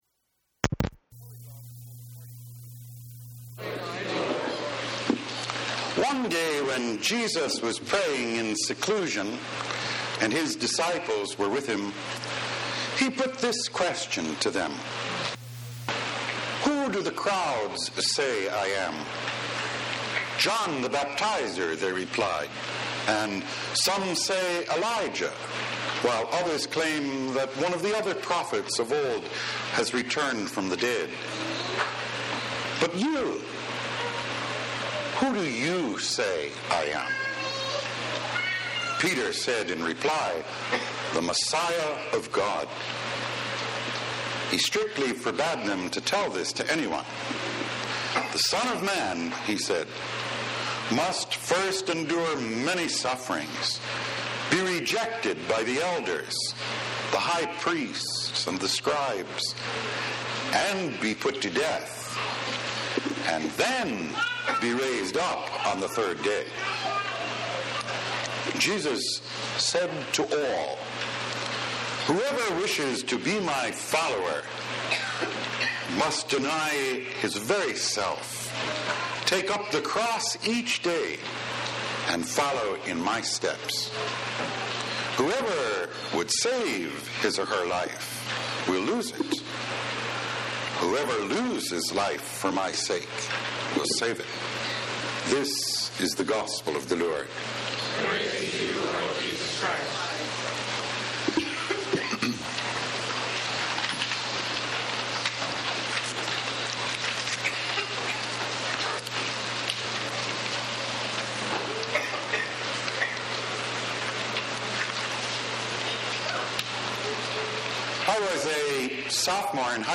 Action – Page 2 – Weekly Homilies